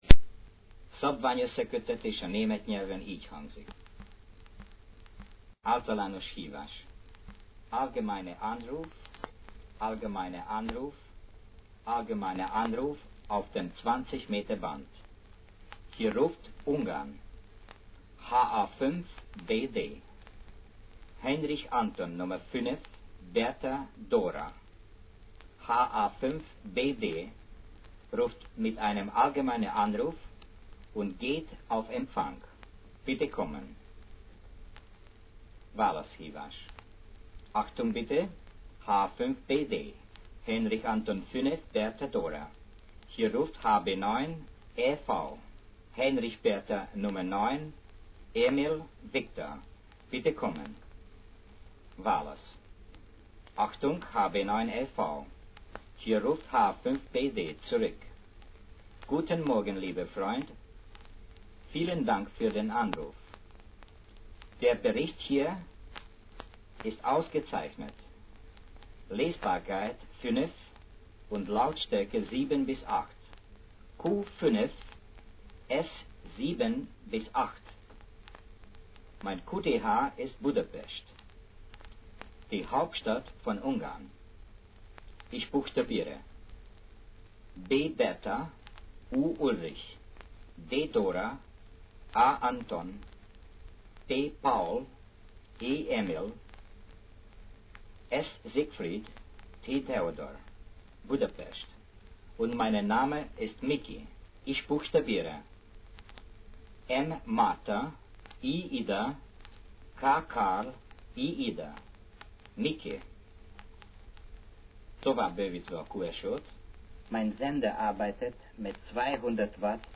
Meghallgatható, egy-egy minta forgalmazás, morzejelekkel, és távbeszélõ üzemmódban.